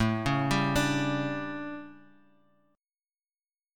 A11 Chord
Listen to A11 strummed